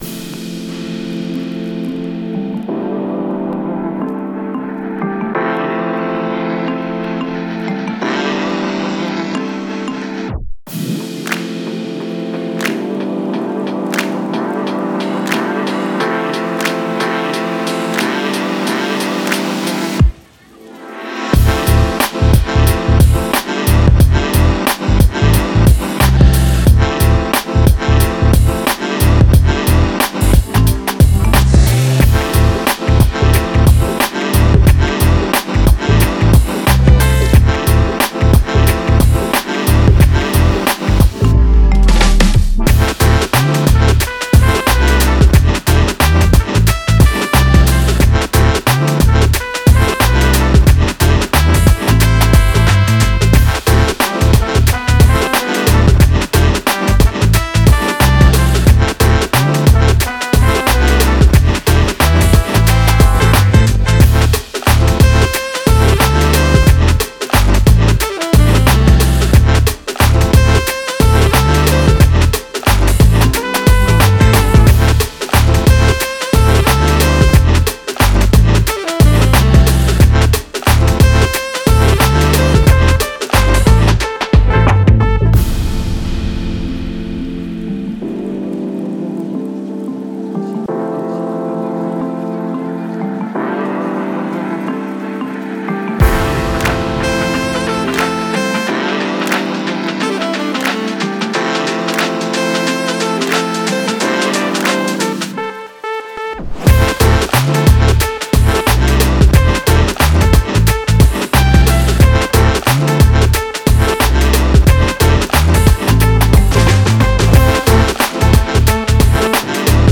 Smooth, perfect for relaxing or creative work.